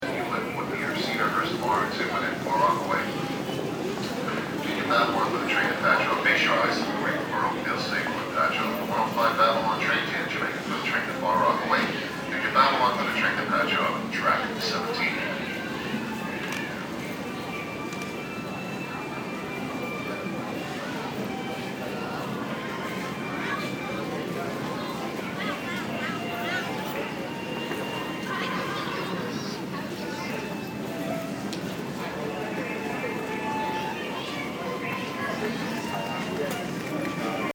Field Recording 8
Location: Penn Station (LIRR Boarding)
Sounds Heard: Boarding announcement, people talking, footsteps, violinist playing
Penn-Station.mp3